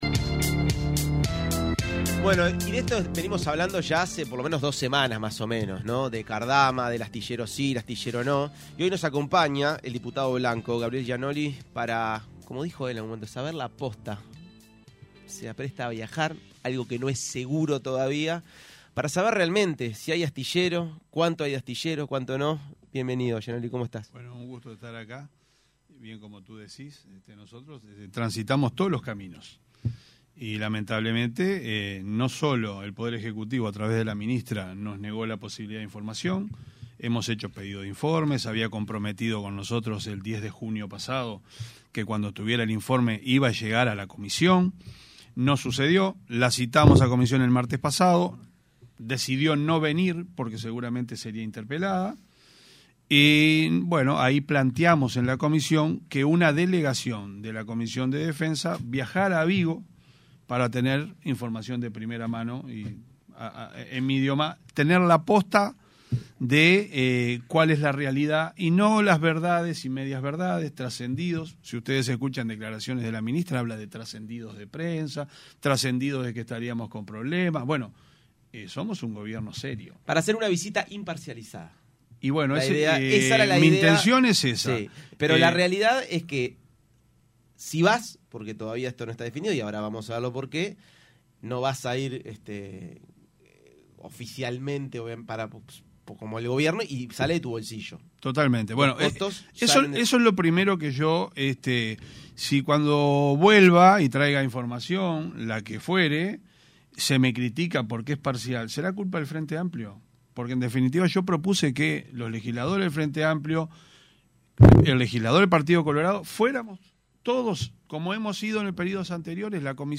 En entrevista con Punto de Encuentro, el diputado nacionalista, integrante de Alianza País, , Gabriel Gianoli criticó la campaña de Álvaro Delgado en las elecciones de 2024, al señalar que recorrió el Interior y habló en pueblos para 20 personas y “no fuimos a La Teja”.